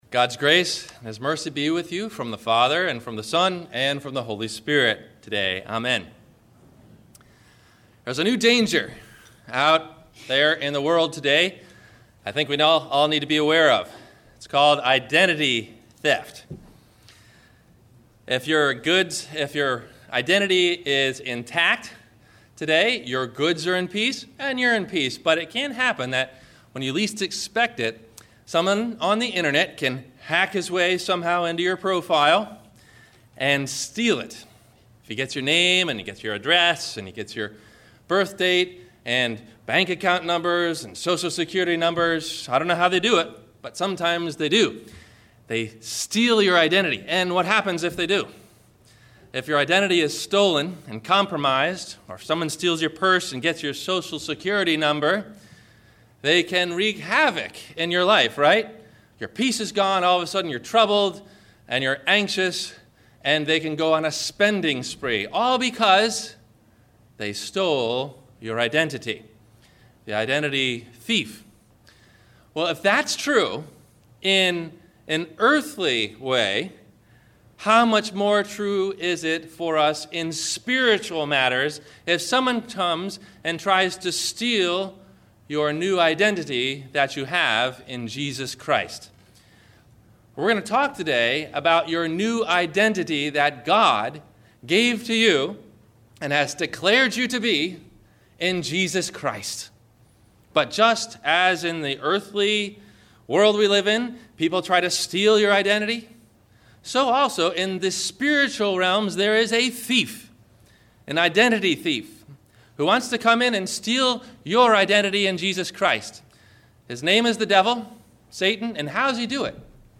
Your New Identity in Christ – Sermon – June 03 2012